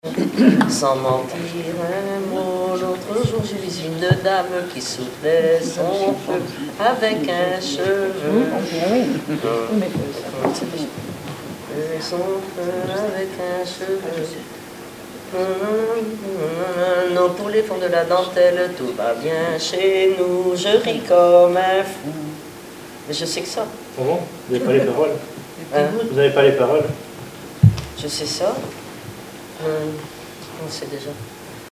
Enfantines - rondes et jeux
formulette enfantine : amusette
Pièce musicale inédite